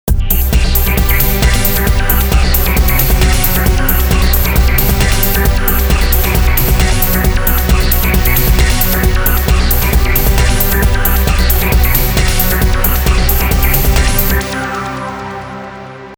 Bass, Pad and Drums played together: